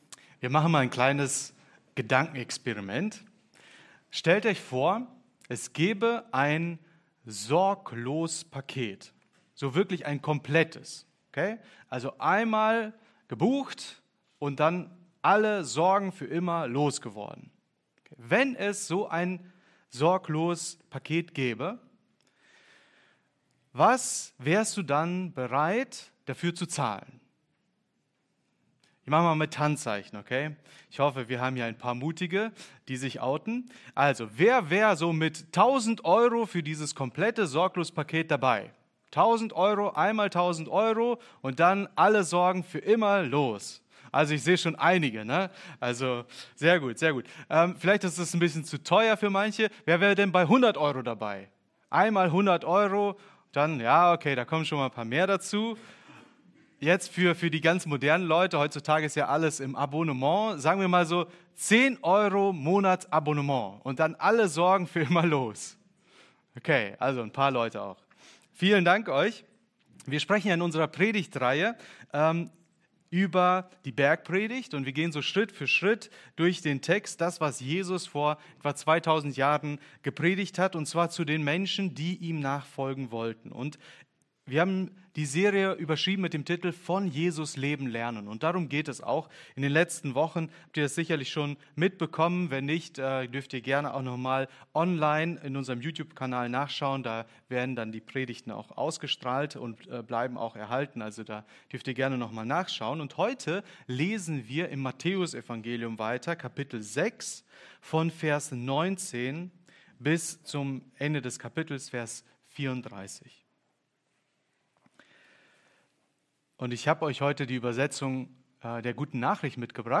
Oktober 2024 Sorgen los werden Prediger